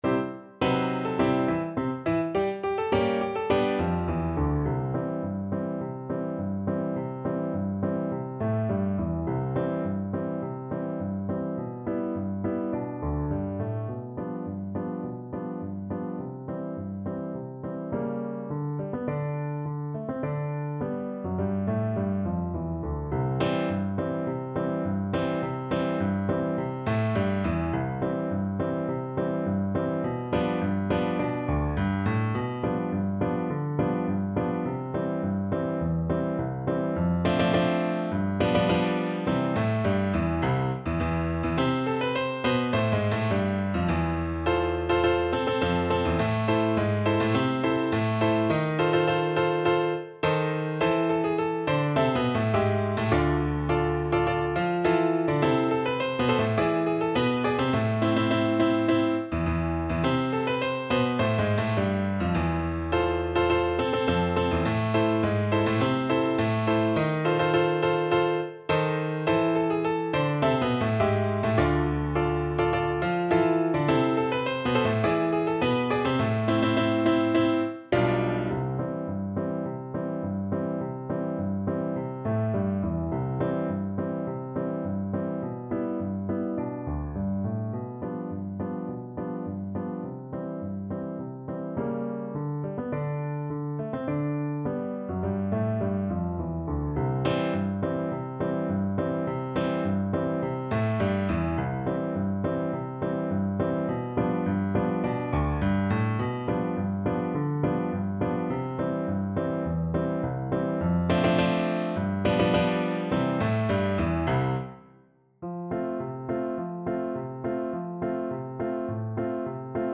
Quick March = c.104
C major (Sounding Pitch) (View more C major Music for Tuba )